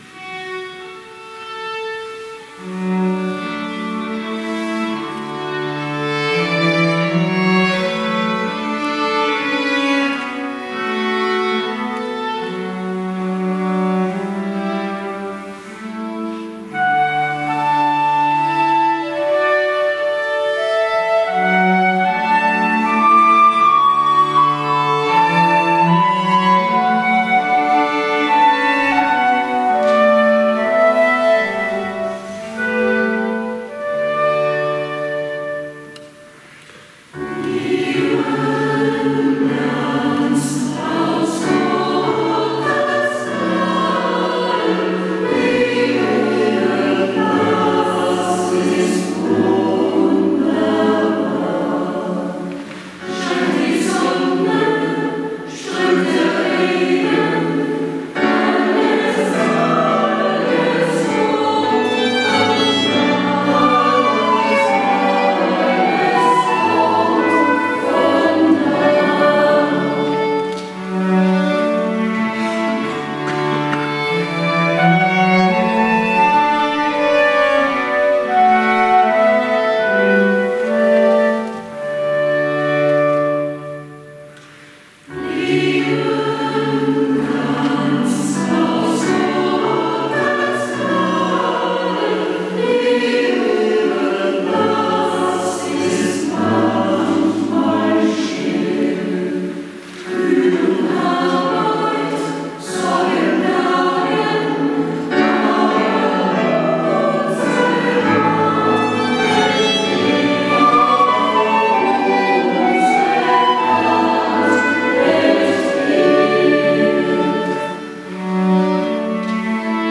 GEISTLICHE ABENDMUSIK
Bedingt durch die Besetzung singen wir vorrangig dreistimmige Chorsätze (Sopran - Alt - Männerstimme).